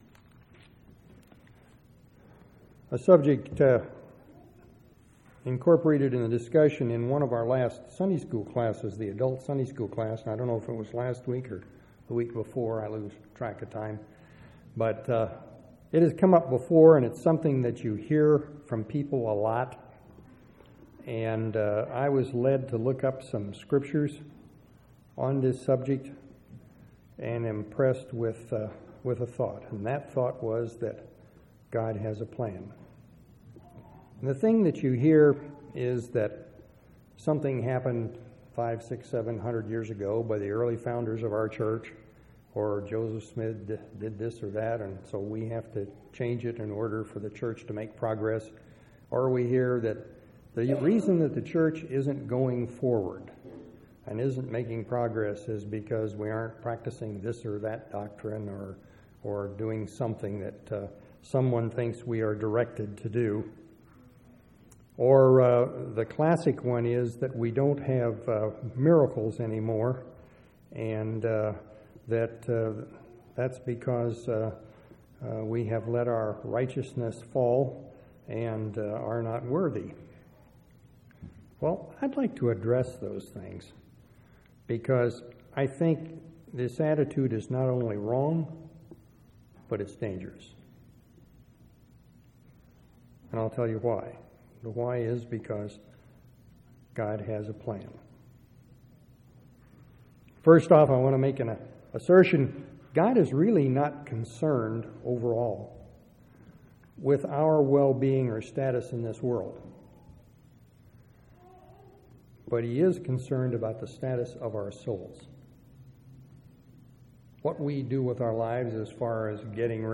6/5/2005 Location: Temple Lot Local Event